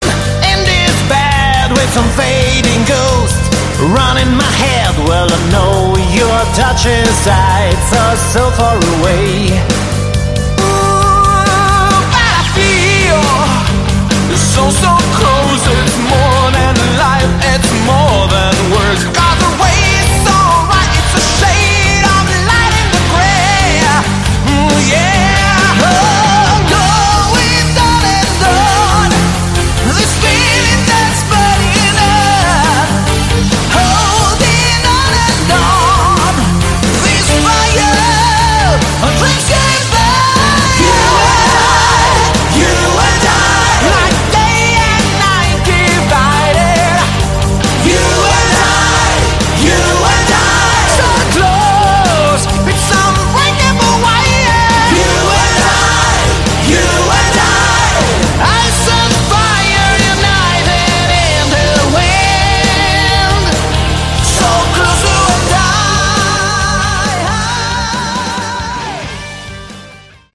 Category: Hard Rock
Great, bombastic AOR effort without any weak point.